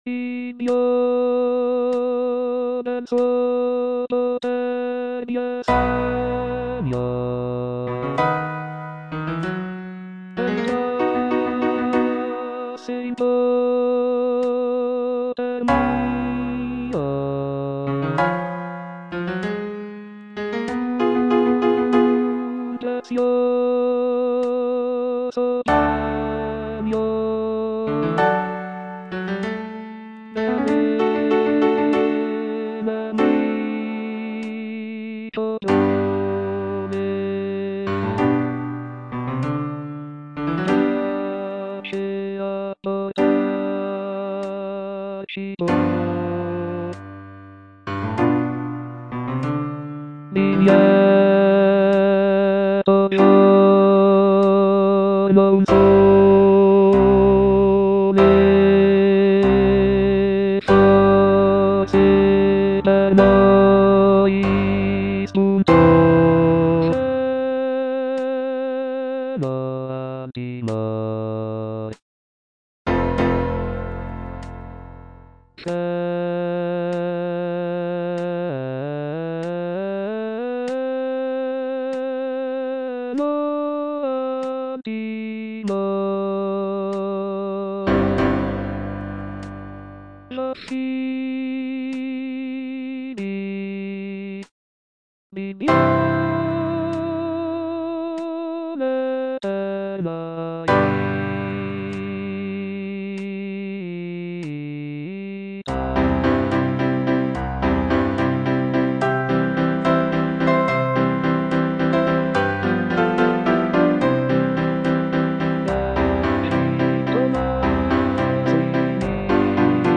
bass II) (Voice with metronome